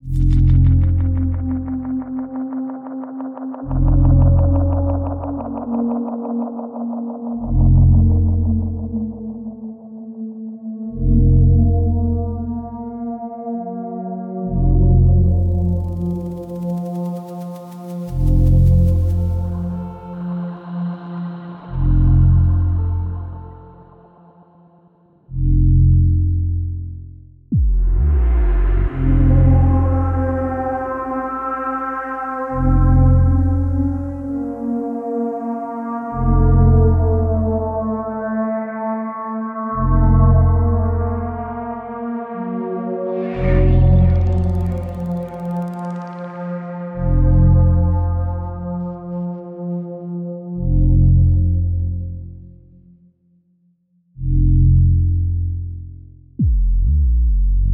DYSTOPIC_menu_ambience_F_phrygdom_133_bpm
ambience audio blade cinematic cyberpunk dark deep drone sound effect free sound royalty free Movies & TV